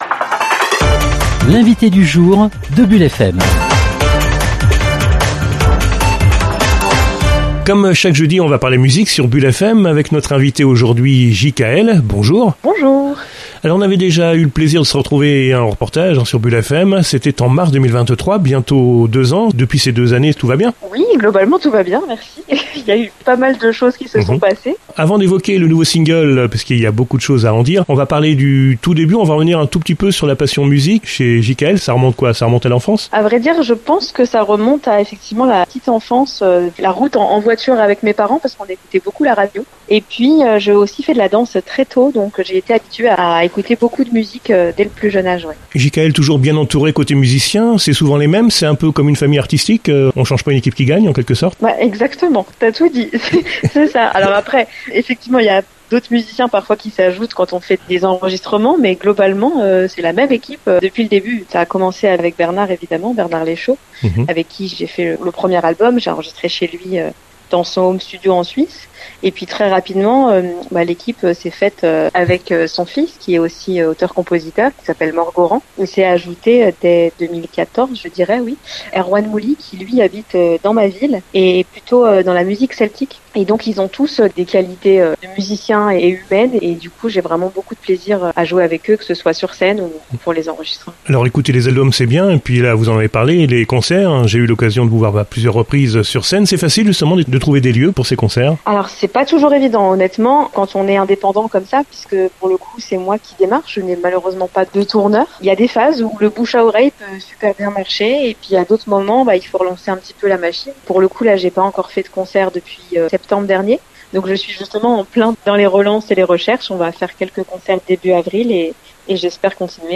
Interview sur Bulle FM